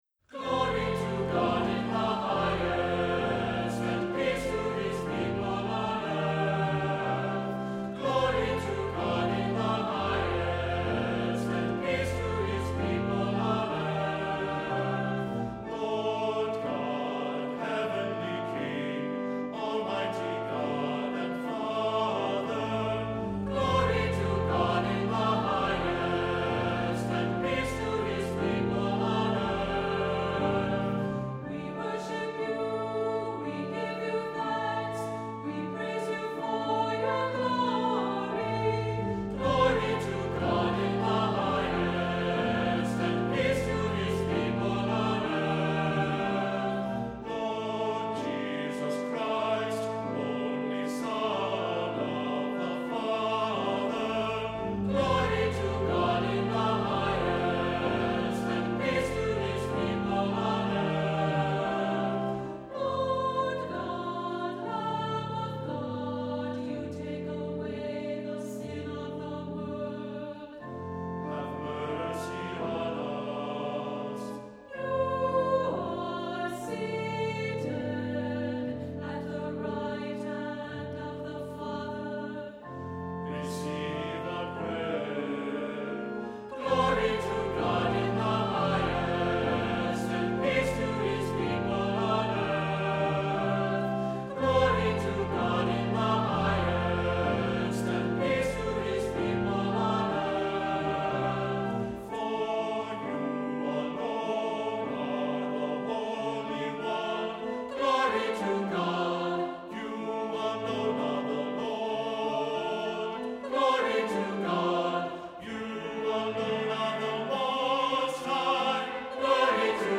Accompaniment:      Organ
Music Category:      Christian
Cantor or soloist part is optional